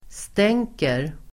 Uttal: [st'eng:ker]